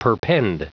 Prononciation du mot perpend en anglais (fichier audio)
Prononciation du mot : perpend